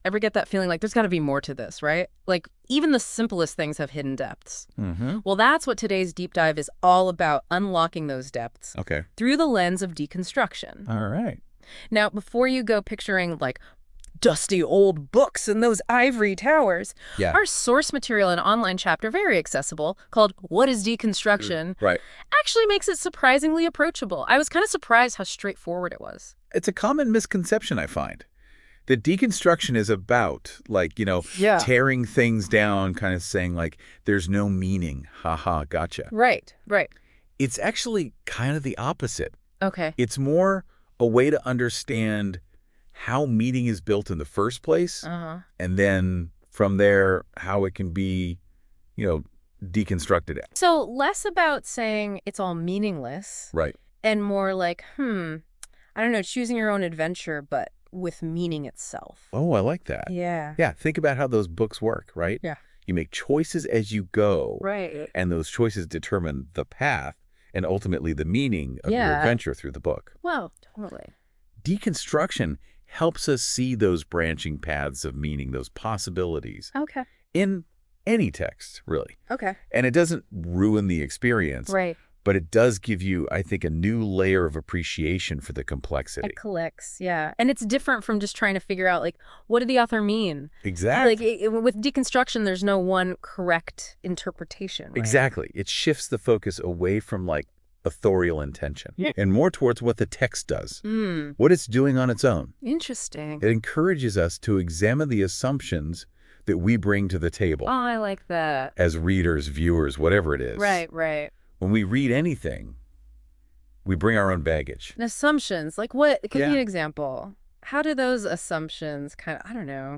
To learn more about deconstruction as a critical method, listen to the podcast below, which was created using Google’s NotebookLM based on the text in “What Is Deconstruction?”